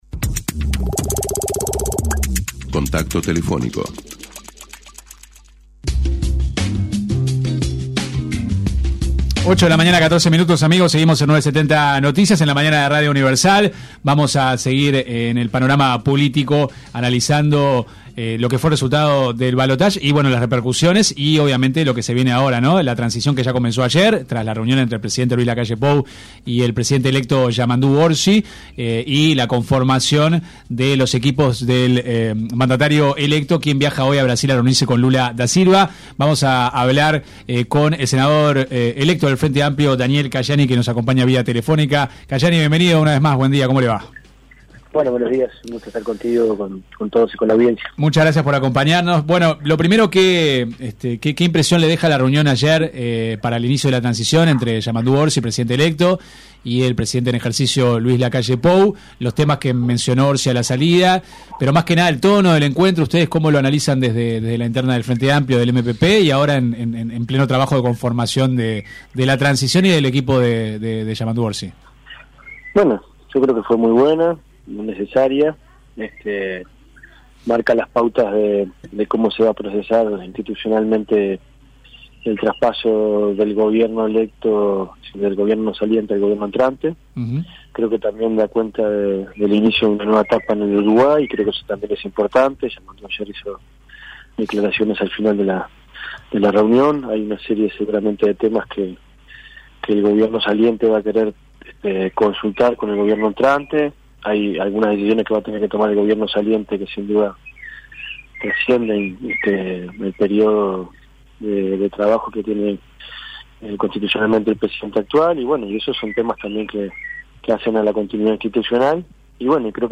Daniel-Caggiani-Tel.mp3